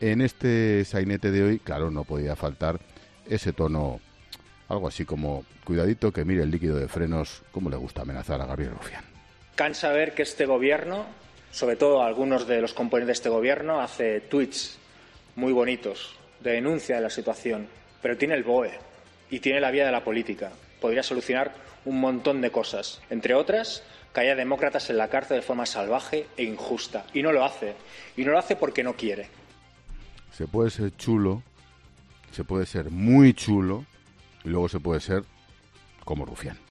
El presentador de 'La Linterna' comenta las declaraciones del portavoz de ERC sobre la pérdida de la inmunidad de Puigdemont
“Se puede ser chulo, se puede ser muy chulo... y luego se puede ser como Rufián”, ha apostillado Expósito tras el audio de Rufián.